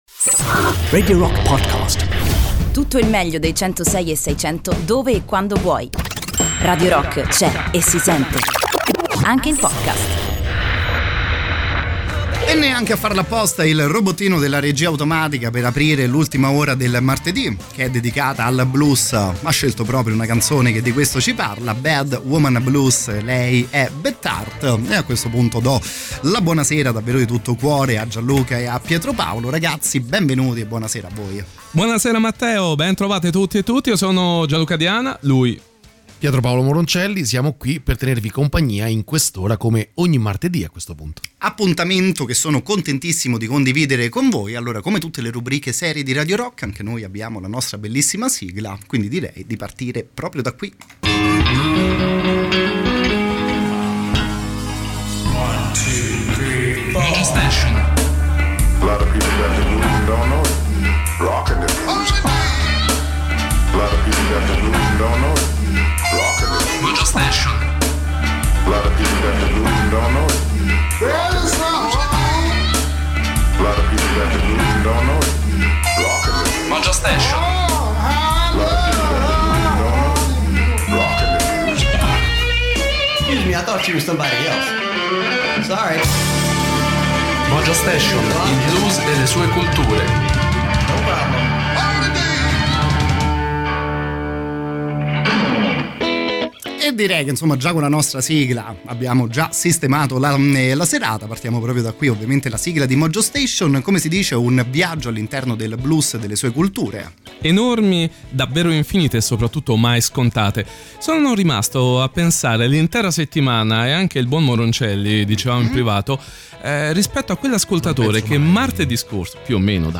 In diretta sui 106e6 di Radio Rock ogni martedì dalle 23:00